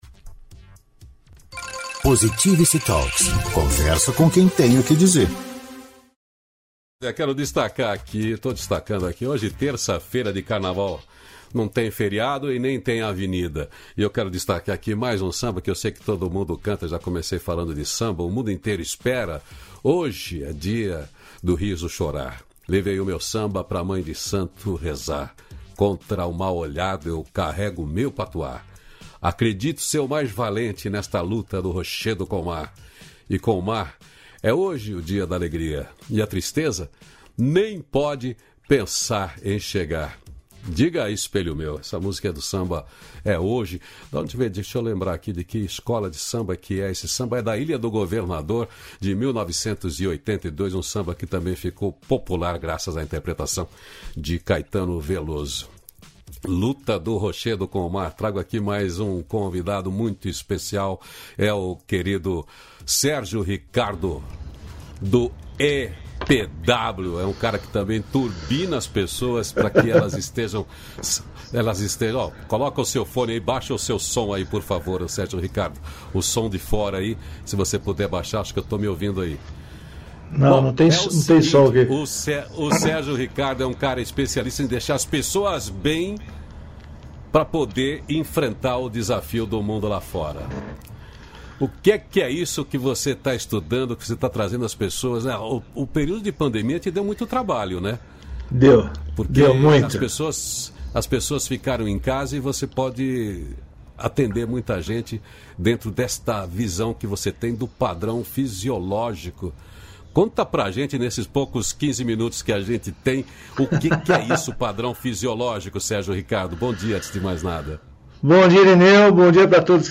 Diálogo Nutritivo